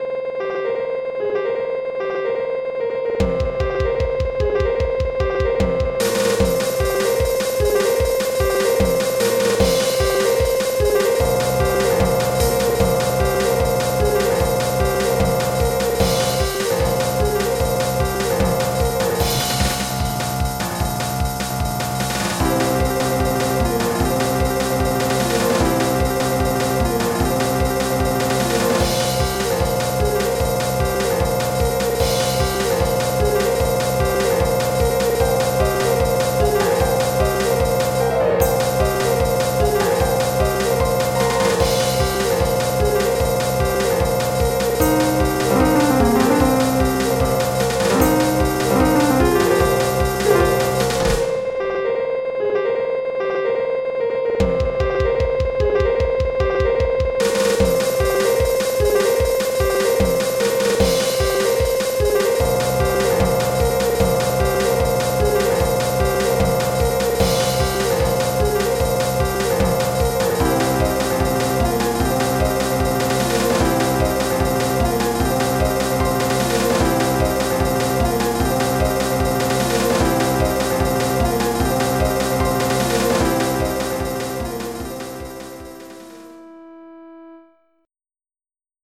2 channels